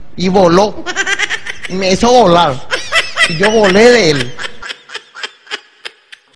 nade.wav